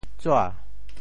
“纸影”用潮州话怎么说？
潮州 zua2 ian2 潮阳 zua2 ian2 潮州 0 1 潮阳 0 1